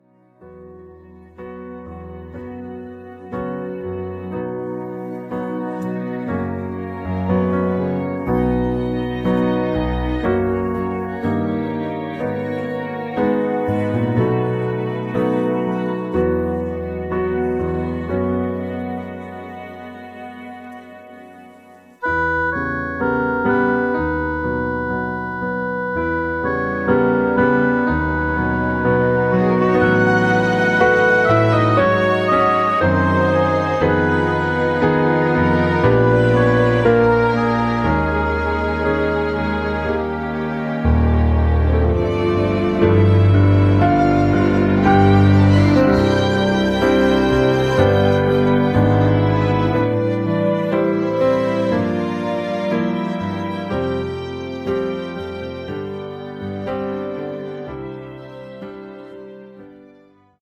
음정 -1키 3:10
장르 가요 구분 Voice Cut